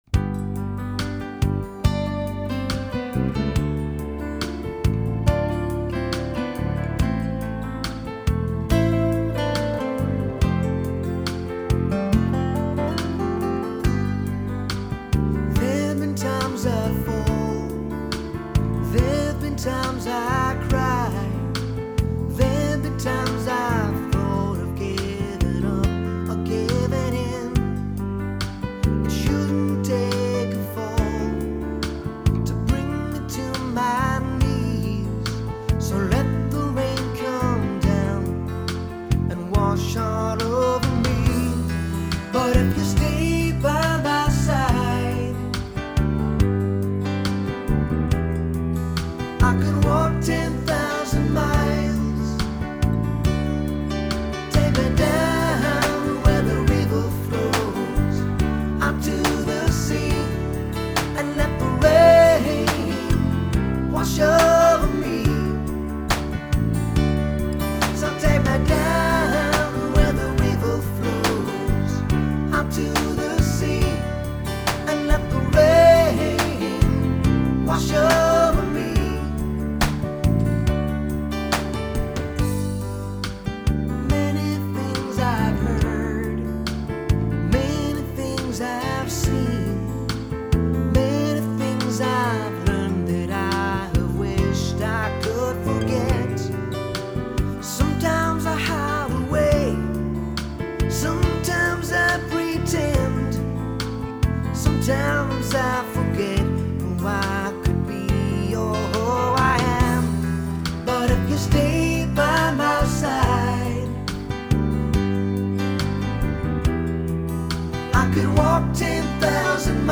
Is that really an F major chord in the chorus? It sounds minor-ish to me.. or at least very different from the F major in the verse.